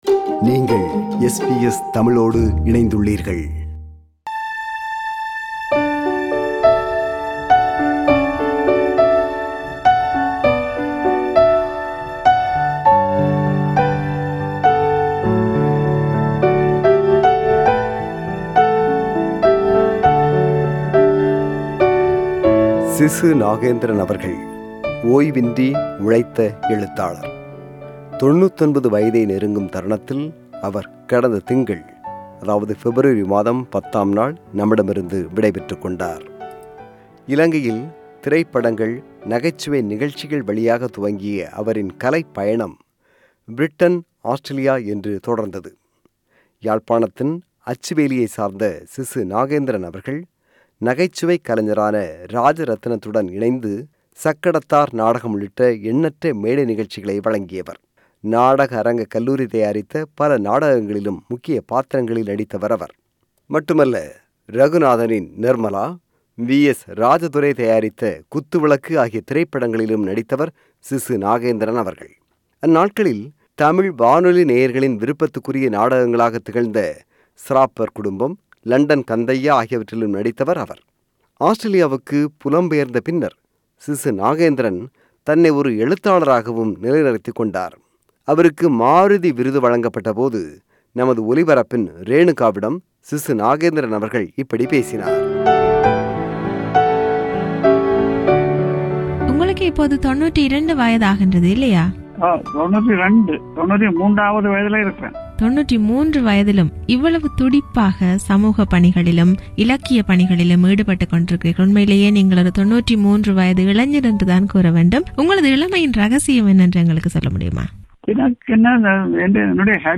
SBS Tamil presents a eulogy